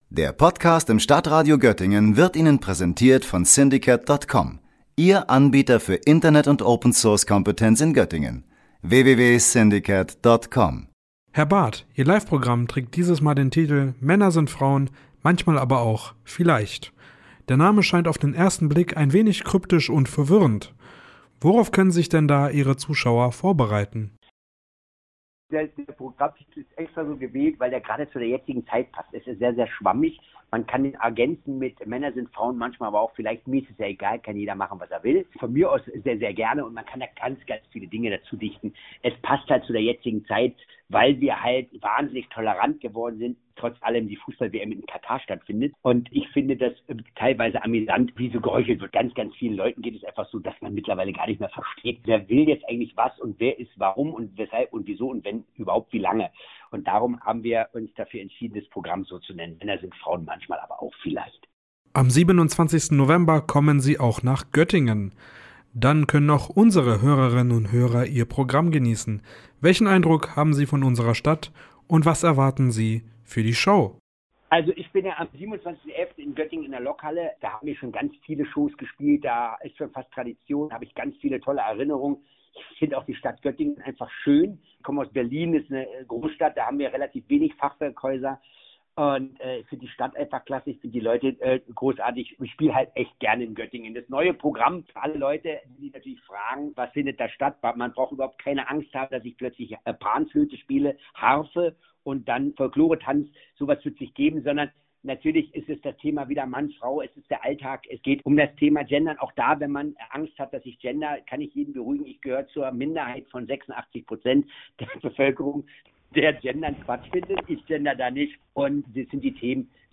Mit seinem neuen Programm „Männer sind Frauen, manchmal aber auch … vielleicht“ tourt er durch die Hallen Deutschlands und zieht viel Publikum zu sich. Worum es in seinem neuen Programm geht, wie der Comedian zum gendern steht und wieso man ihn auch als Drag-Queen sehen konnte, das fand unser Reporter